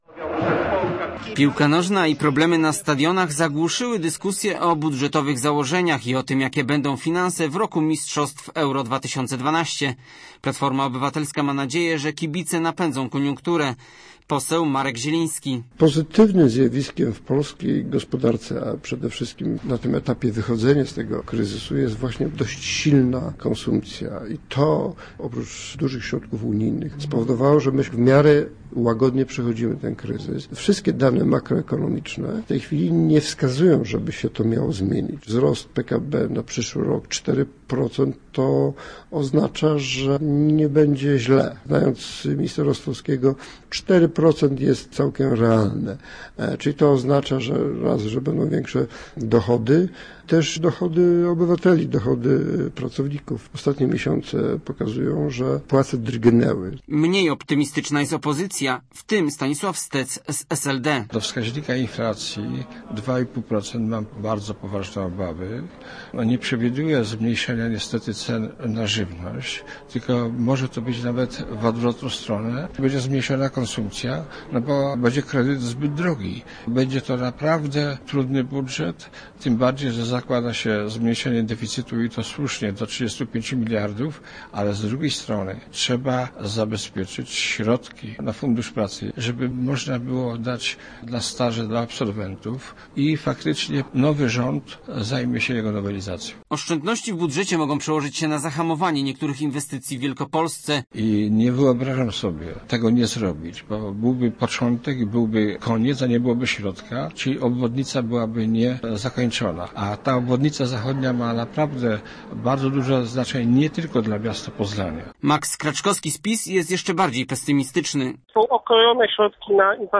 A kilka dni temu rząd przyjął założenia budżetowe na 2012. Nasz reporter przepytał polityków jaki budżet szykuje nam rząd i jak ma się on do życia w Wielkopolsce.